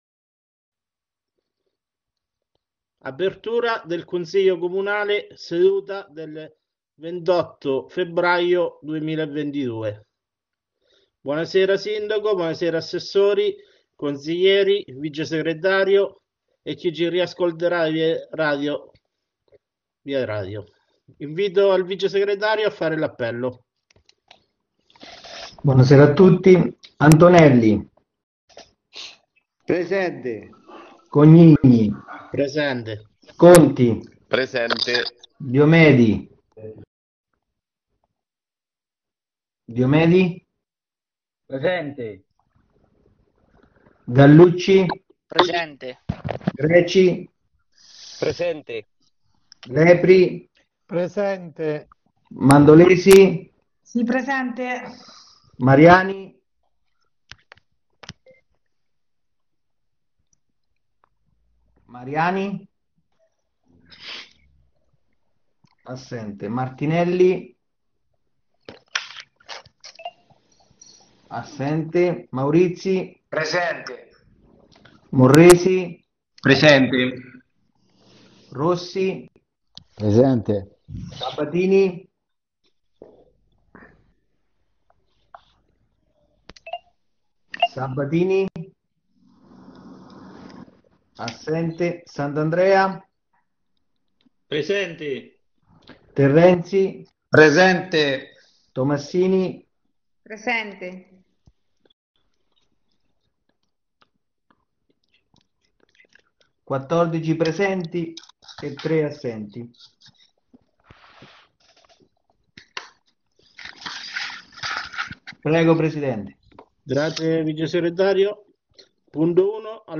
Registrazioni audio dei consigli comunali di Sant'Elpidio a Mare